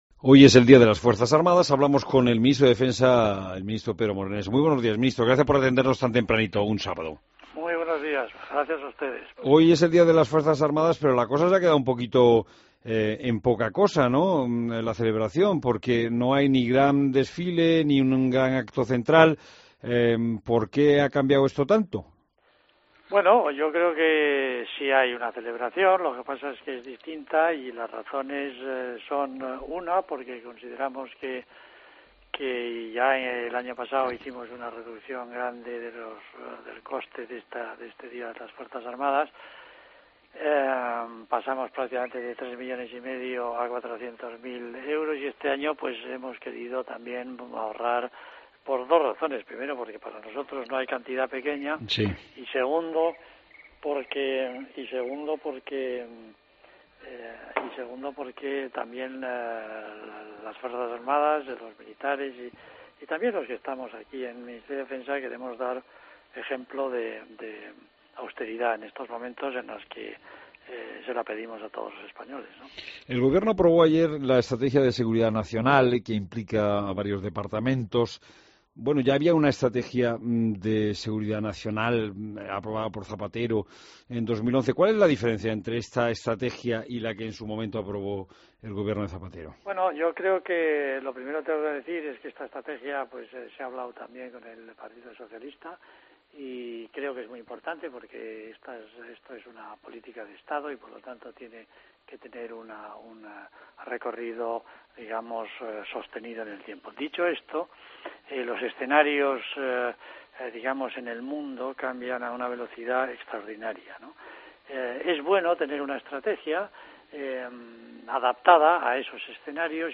Entrevista a Pedro Morenés en La Mañana Fin de Semana